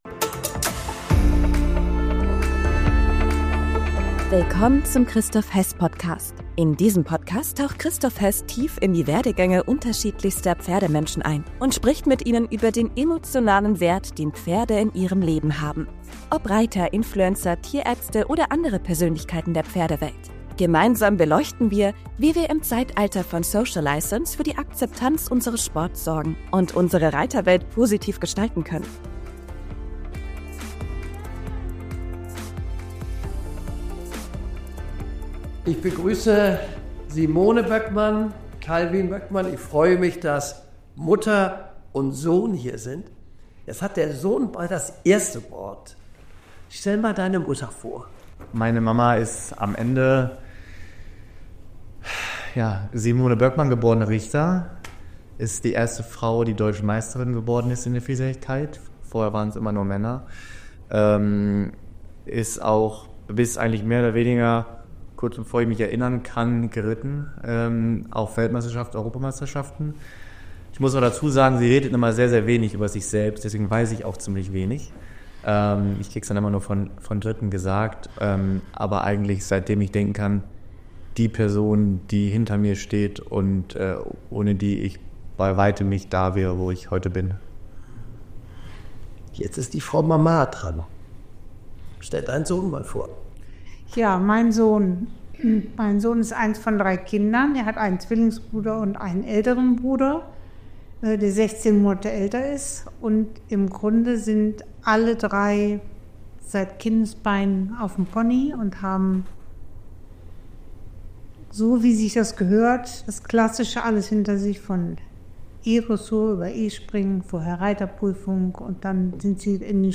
Ein Gespräch voller Tiefgang, Erfahrung und Inspiration – aus dem Leben zweier Generationen im Sattel.